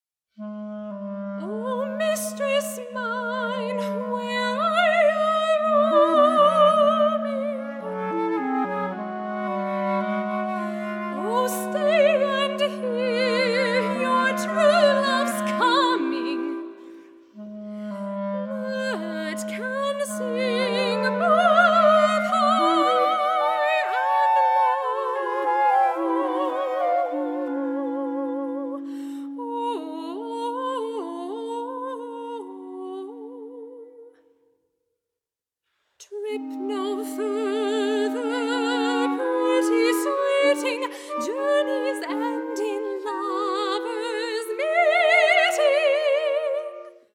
soprano
viola
flute
clarinet